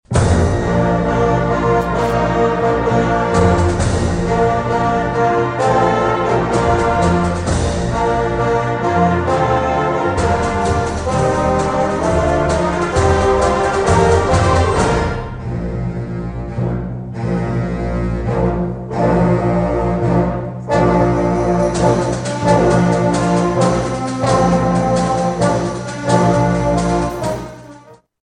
High school musicians from across Kansas performed at Emporia State University on Saturday.
The students gathered at ESU on Saturday morning and practiced for several hours before performing along with the ESU Jazz Ensemble for a free concert.